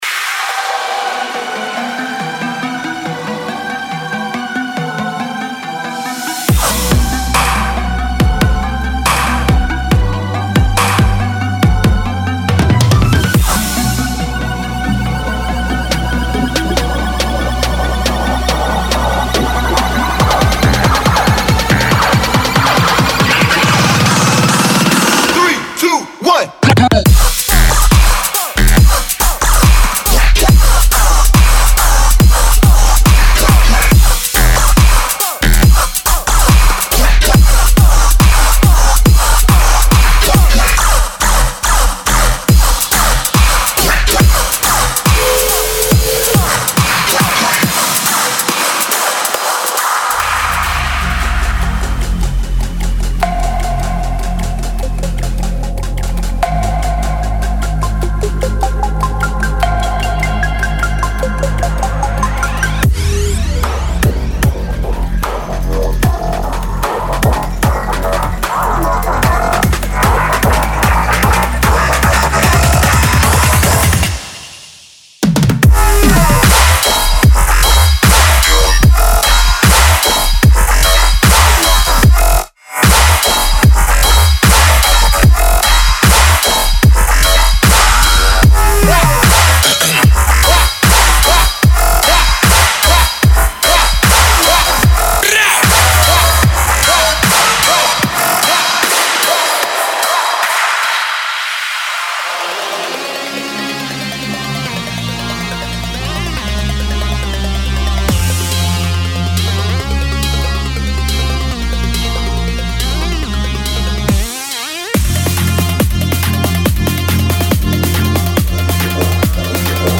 享受新编程的血清预设，构造套件，合成器，贝司，鼓循环等等，并加入目前发展最快的子类别之一Riddim。
•61个Riddim One-Shots（鼓，贝斯，合成器）
•77 Riddim Synth和Bassloops（干湿）